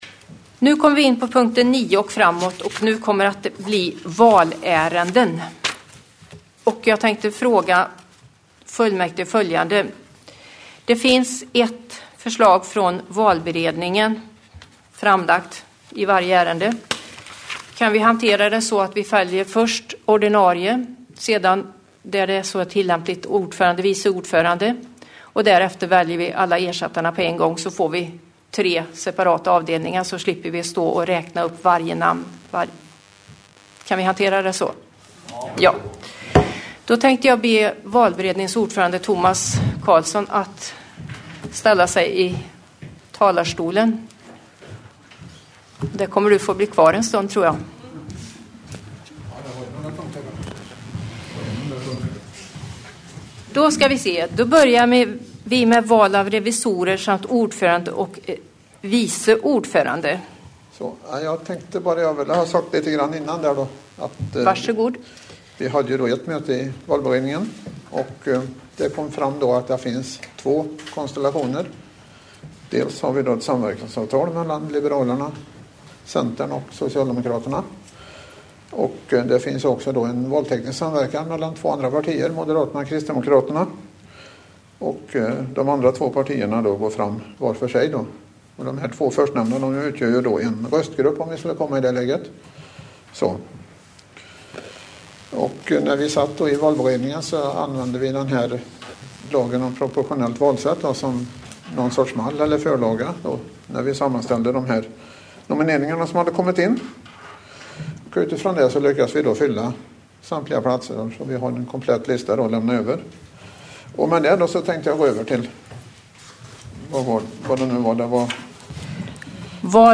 webbsändning från Tibor kommunfullmäktige